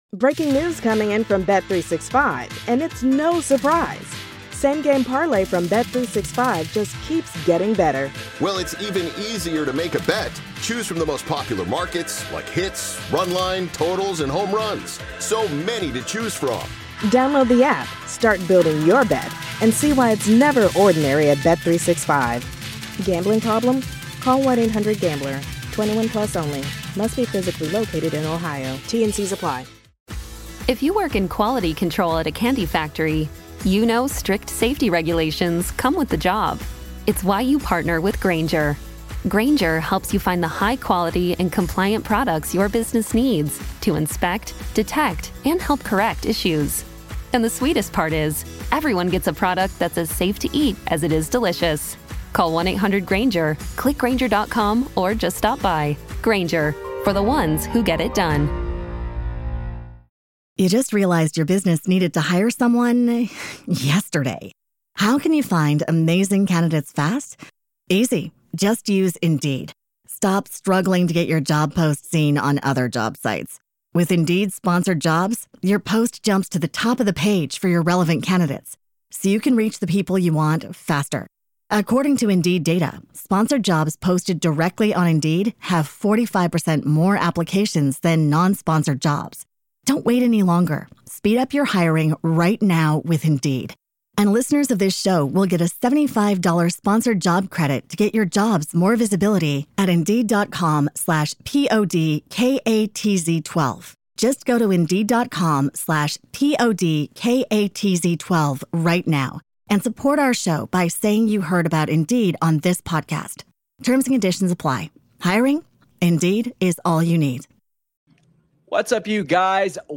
Shoot Interviews Apr 8